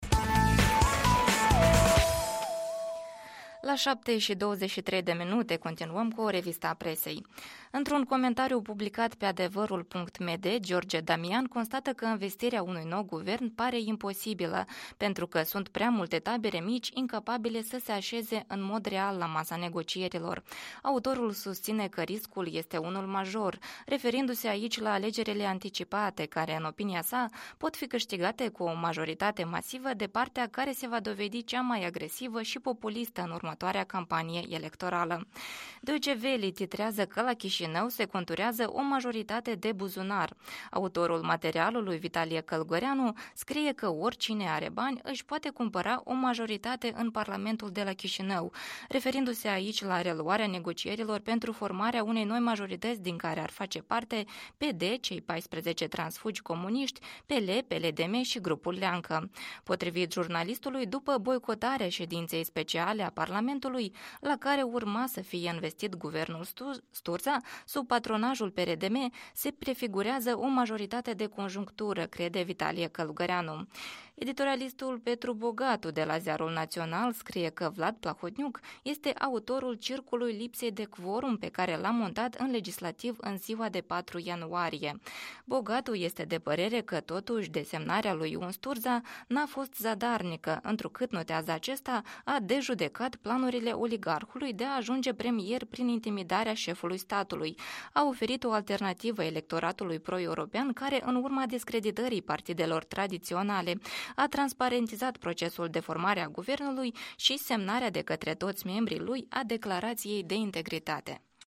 Revista presei matinale